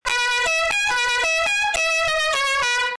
Melodick� klakson LA CUCARACHA s kompresorem, fanf�ra, 12 V - V�PRODEJ !!!
lacucaracha.mp3